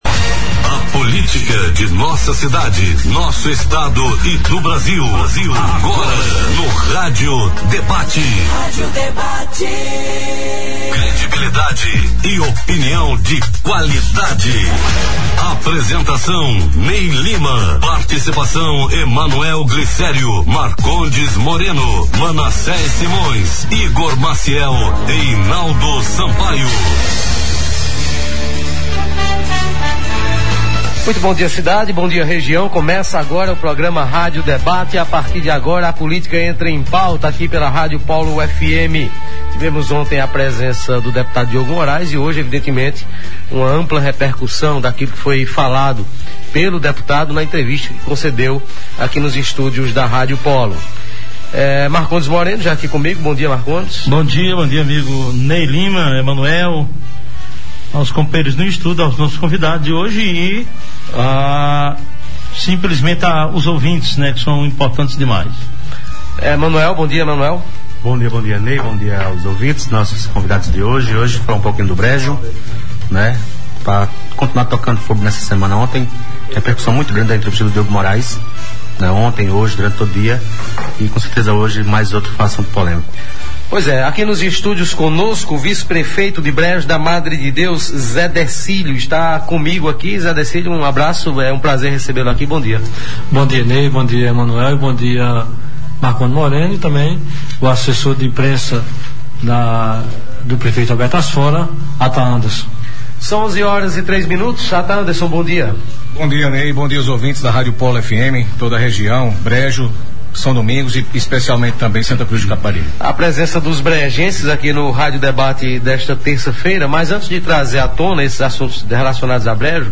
Rádio Debate aborda “polêmica dos urubus” em Brejo e “dança das cadeiras” na gestão Vieira Na edição desta terça-feira (10), o Rádio Debate, veiculado pela Polo FM, abordou os bastidores da politica local e da cidade de Brejo da Madre de Deus. O destaque ficou por conta da polêmica envolvendo o transporte de carnes em caminhão aberto do matadouro de Brejo para à feira livre do distrito de São Domingos, caminhão este que serviu até como poleiro de urubus evidenciando um grave problema de saúde pública, presente naquela localidade. Para falar sobre a polêmica, estiveram presentes nos estúdios da Polo FM